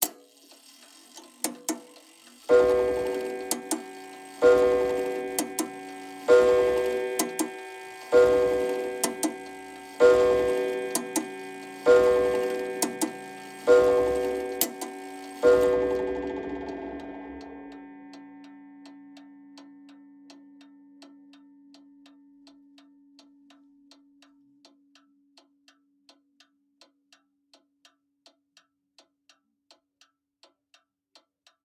Mantel Clock With French P….mp3 📥 (1.21 MB)